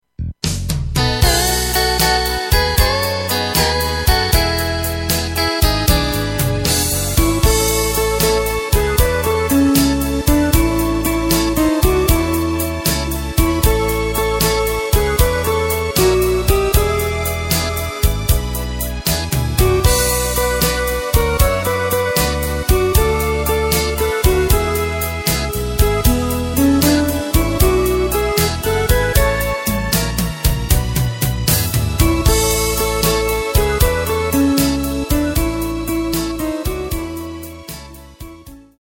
Takt:          4/4
Tempo:         116.00
Tonart:            A
Schlager aus dem Jahr 2005!